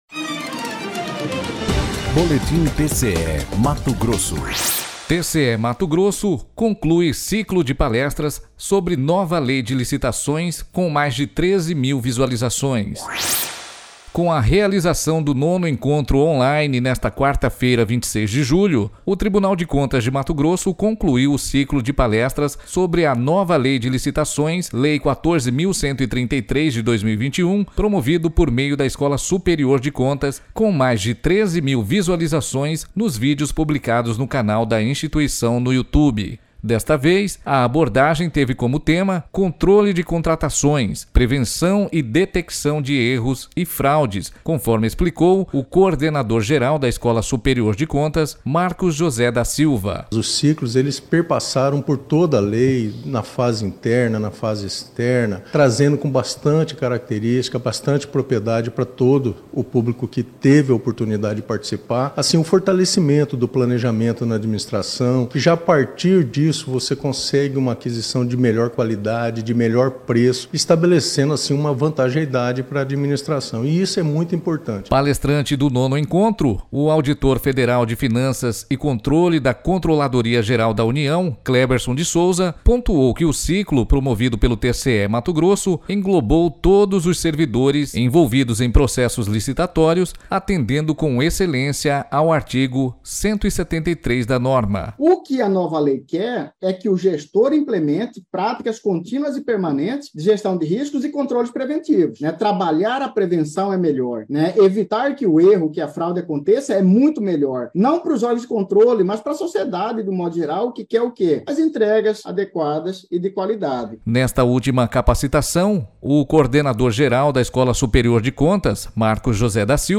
Sonora: